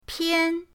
pian1.mp3